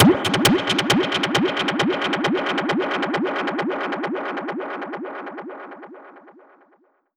Index of /musicradar/dub-percussion-samples/134bpm
DPFX_PercHit_D_134-01.wav